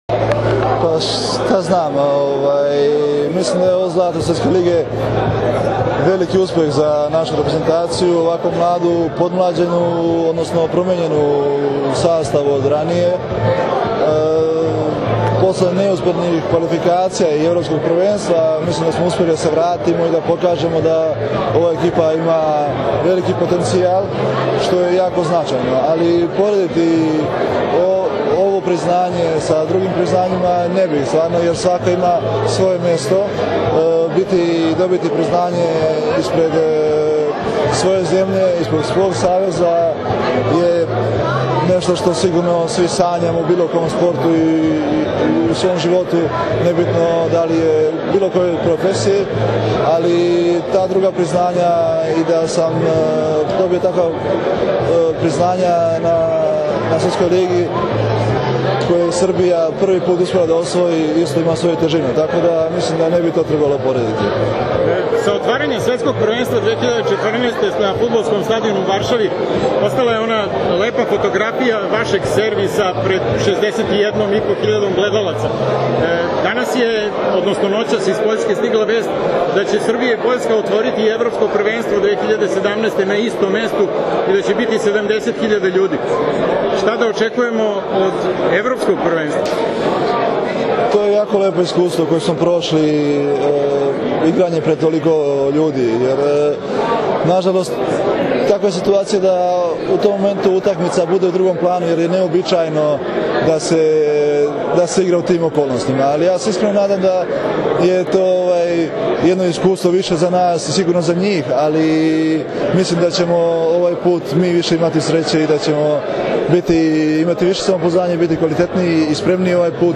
U beogradskom hotelu Metropol danas je održan tradicionalni Novogodišnji koktel Odbojkaškog saveza Srbije, na kojem su podeljenje nagrade najboljim pojedincima i trofeji “Odbojka spaja”.
IZJAVA MARKA IVOVIĆA